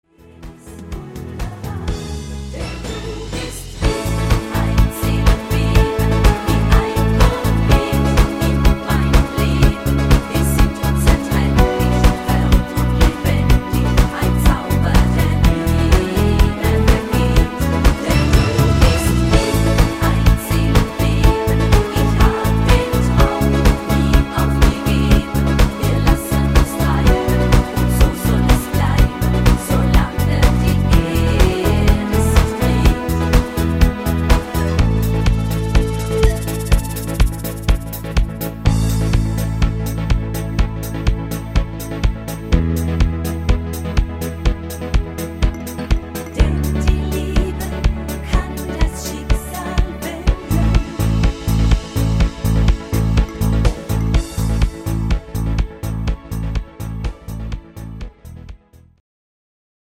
Rhythmus  Party Discofox
Art  Deutsch, Popschlager, Weibliche Interpreten